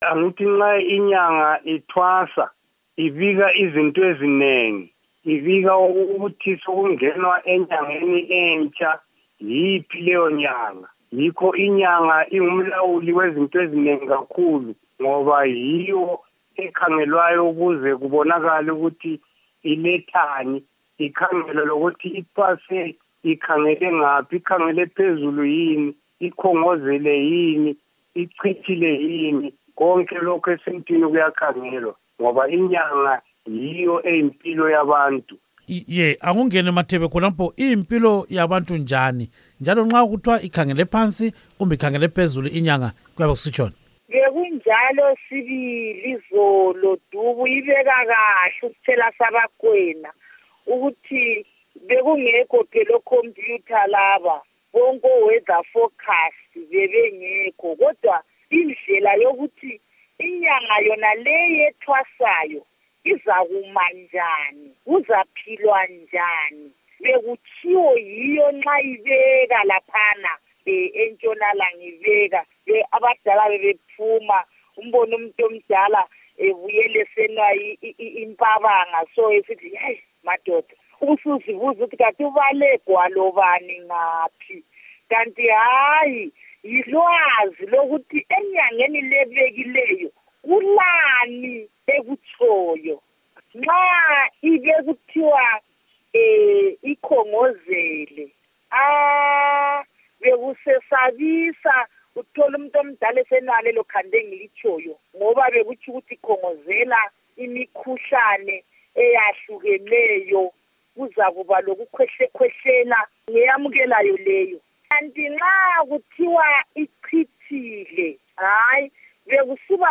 Ingxolo